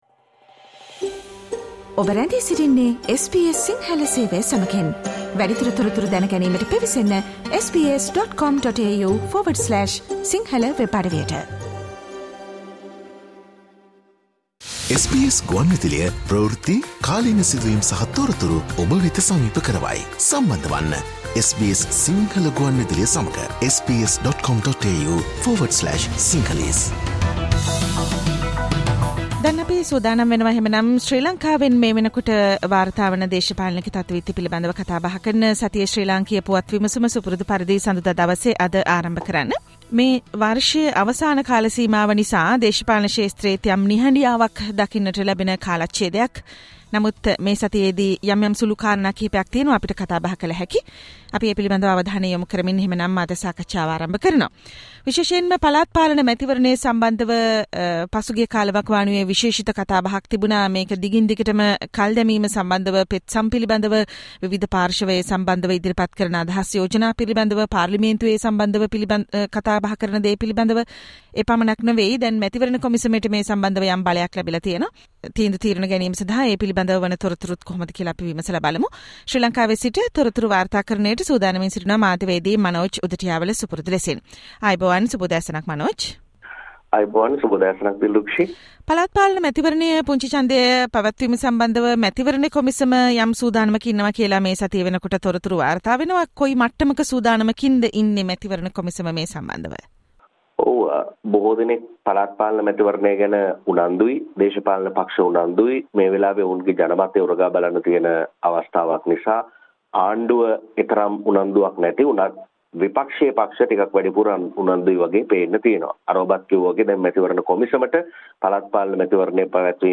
Discussions are underway among several parties to join before the provincial council election_ Sri Lankan News Wrap_ SBS Sinhala_ 19 Dec 2022